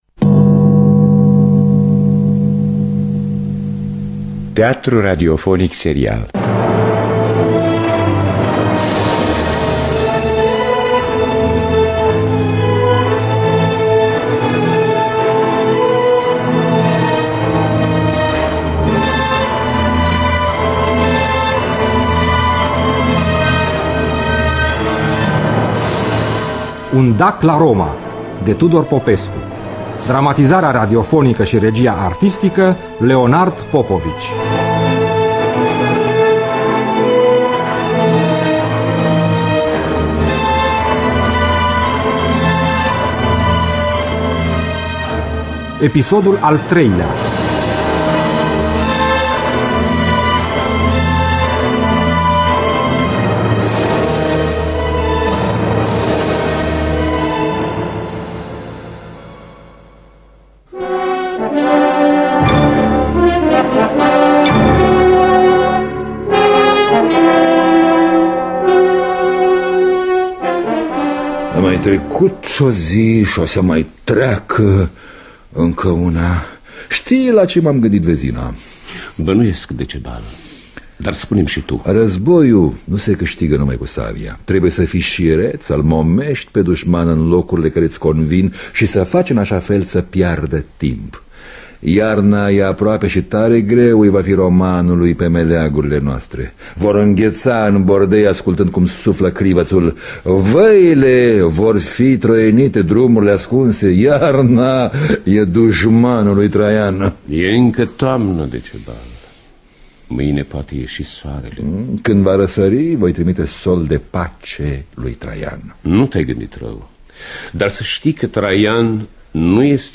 Dramatizarea radiofonică